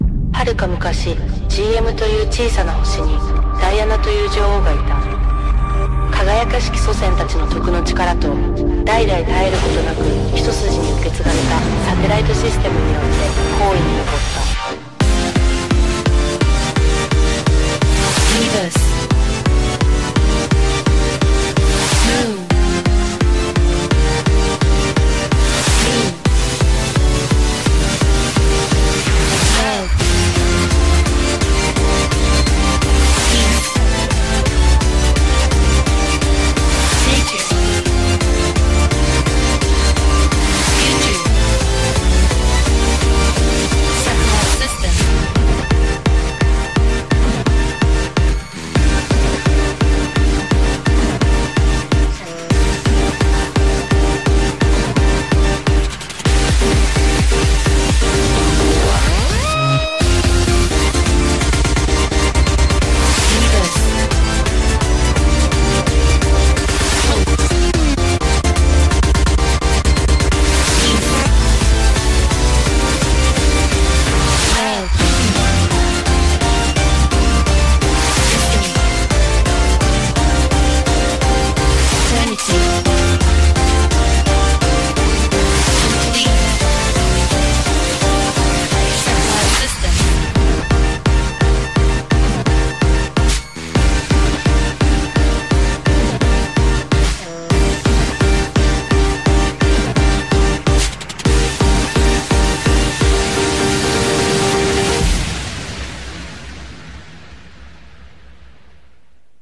BPM128
Audio QualityCut From Video